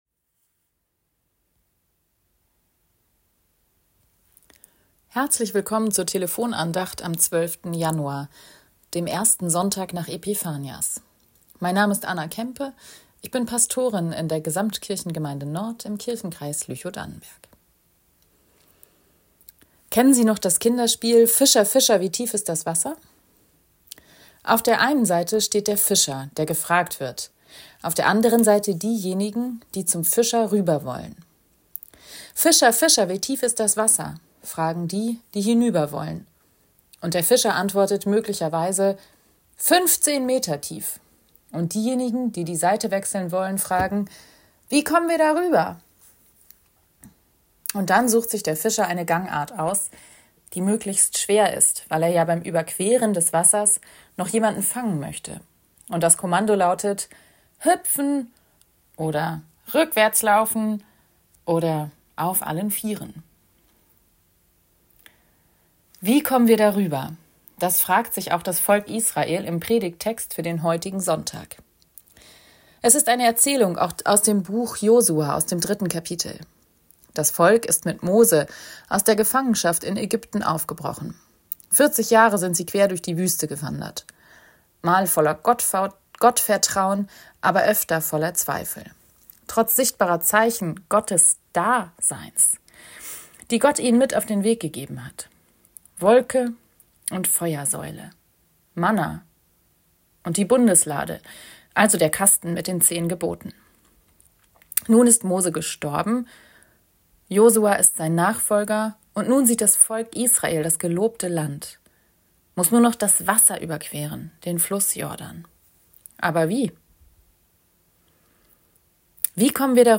Fischer, Fischer, wie tief ist das Wasser ~ Telefon-Andachten des ev.-luth.